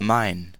Ääntäminen
Vaihtoehtoiset kirjoitusmuodot (slangi) mah Synonyymit me mah Ääntäminen stressed: IPA : /maɪ/ US : IPA : [maɪ] unstressed: IPA : /maɪ/ unstressed: IPA : /mə/ IPA : /mɪ/ IPA : /mi/ Cockney: IPA : /mɪ/ IPA : /mi/